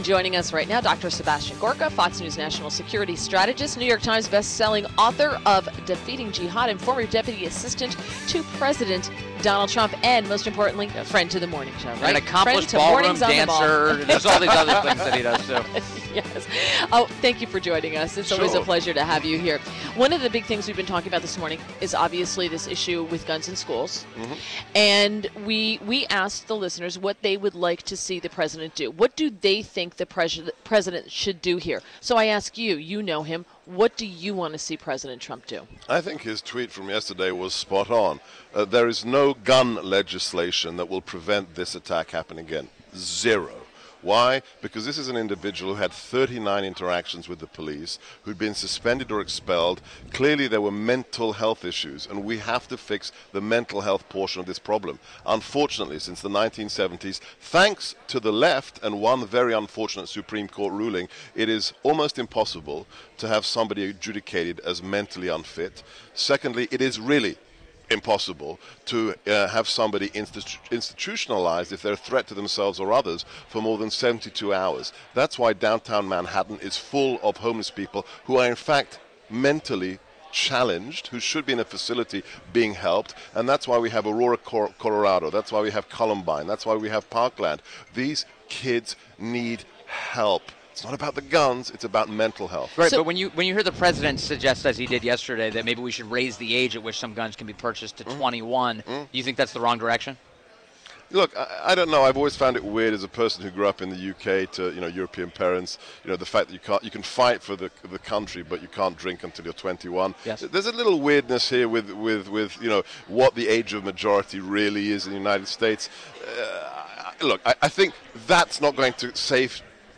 WMAL Interview - CPAC DR. SEBASTIAN GORKA - 02.23.18
INTERVIEW - DR. SEBASTIAN GORKA - FOXNews National Security Strategist, NYT Bestseller author of "DEFEATING JIHAD" and Fmr deputy assistant to President Donald Trump - discussed President Trump’s reaction to Parkland shooting